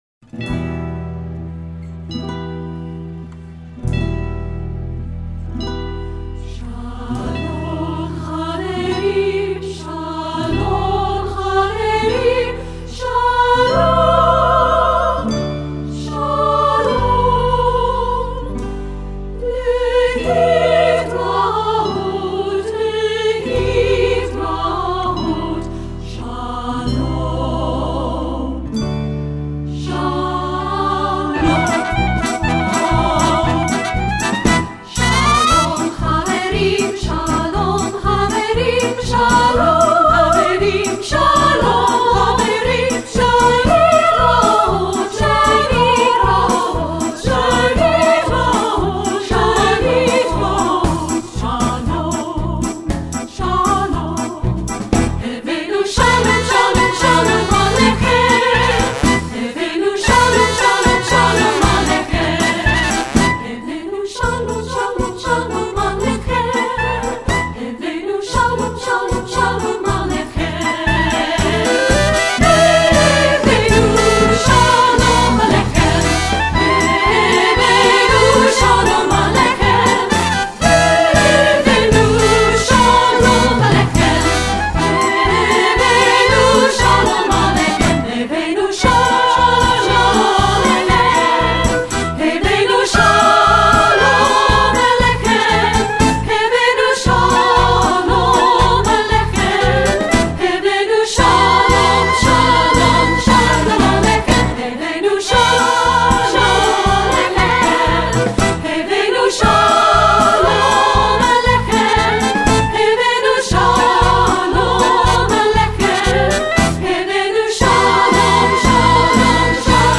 Composer: Israeli Folk Song
Voicing: 2-Part